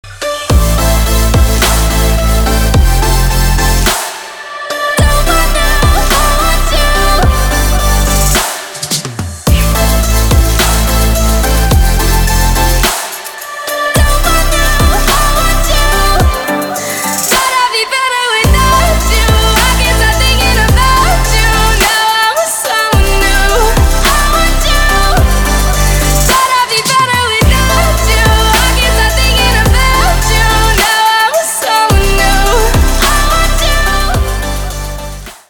• Качество: 320, Stereo
поп
женский вокал
Electronic
future bass